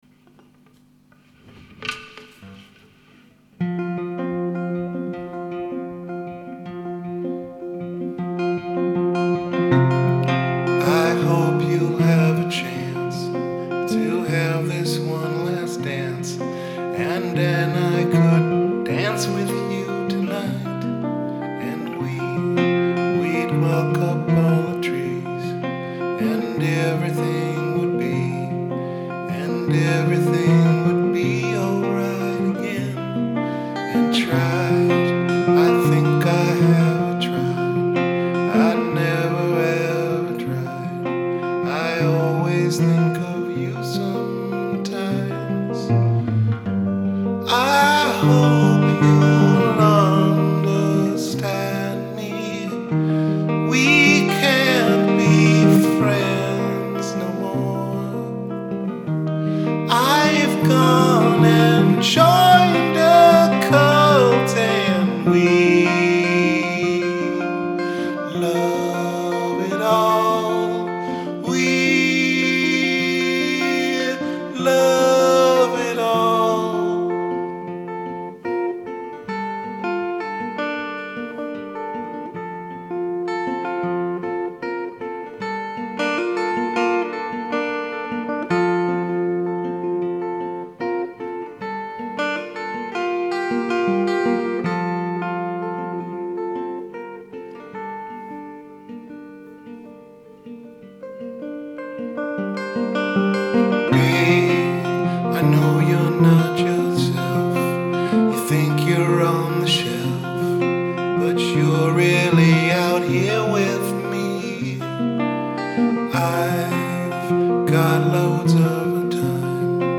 first recording of a completely new song idea
Rehearsal recordings